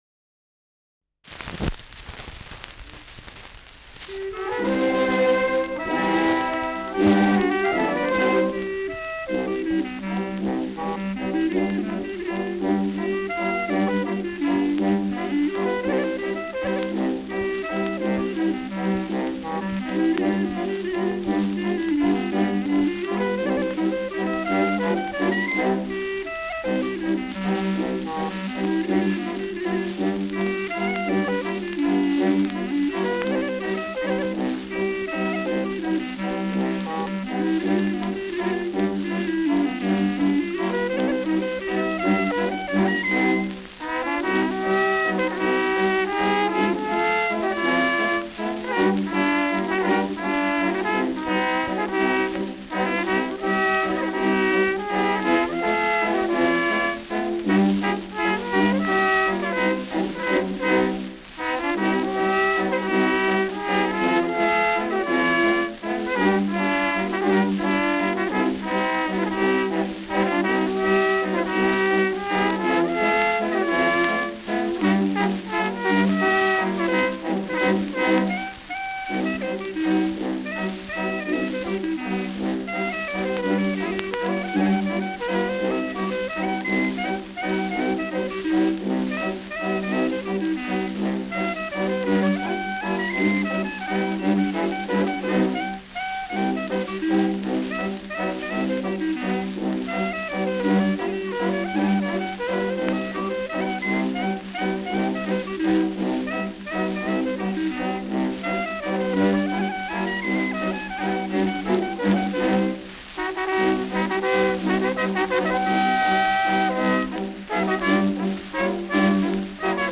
Schellackplatte
Rauschen präsent
Kapelle Alpenklang, München (Interpretation)